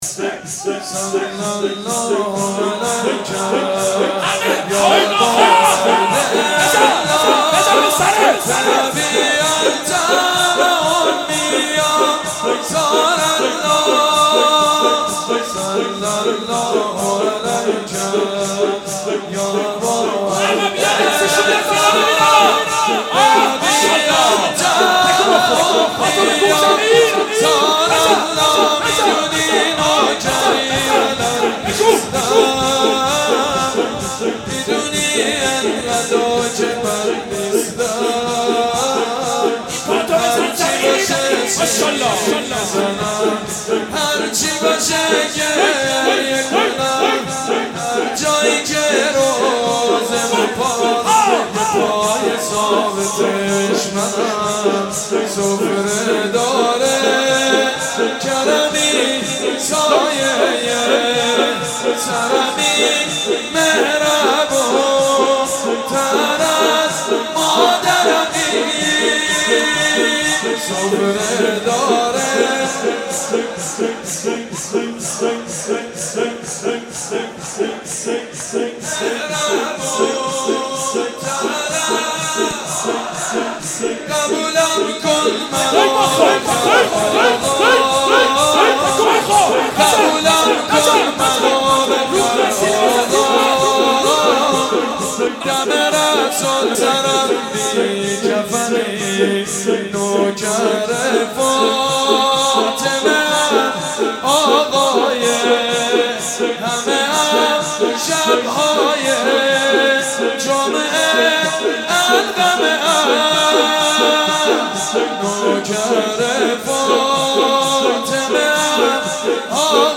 عقیق: مراسم عزاداری شهادت حضرت زینب(سلام الله علیها) با حضور هزارن عزادار در هیئت خادم الرضای شهر قم برگزار شد.
در ادامه حاج سید مجید بنی فاطمه و حاج حسین سیب سرخی به نوحه خوانی و ذکر مصیبت حضرت ام المصائب پرداختند.
بخش اول-  نوحه حاج سید مجید بنی فاطمه و حاج حسین سیب سرخی